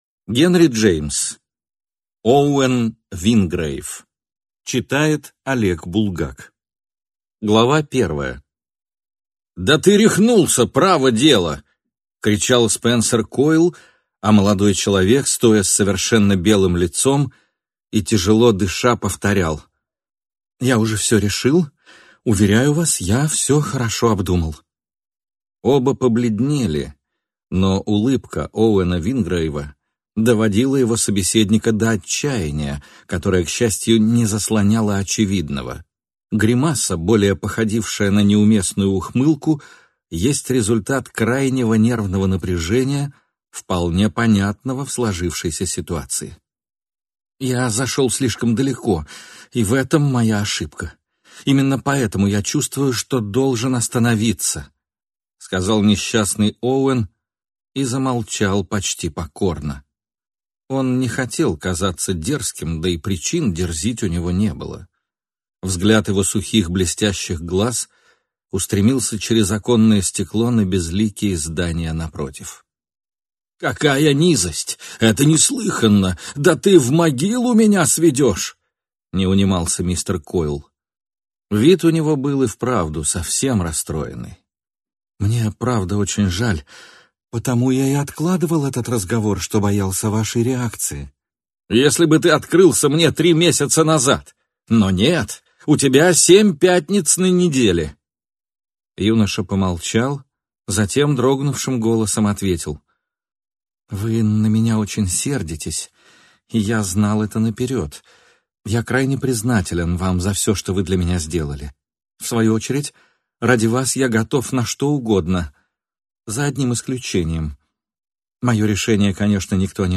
Аудиокнига Оуэн Вингрейв | Библиотека аудиокниг